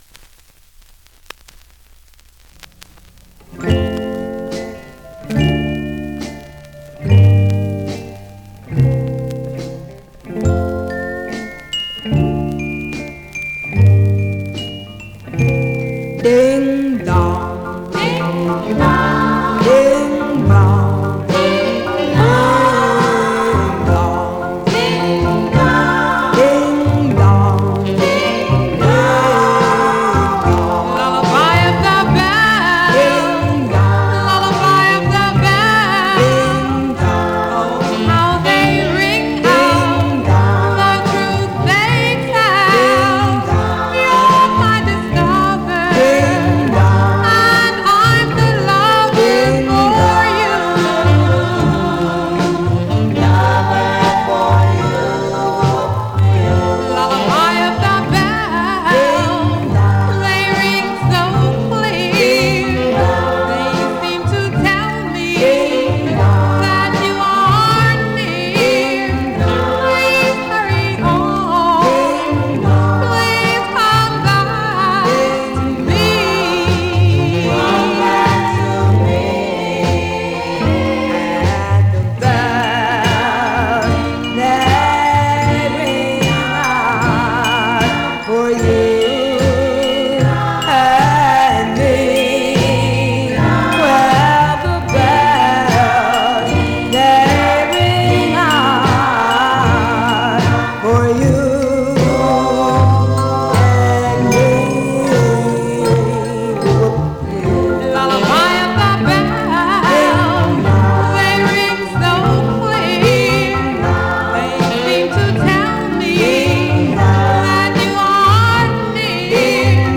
Stereo/mono Mono
Some surface noise/wear
Black Female Group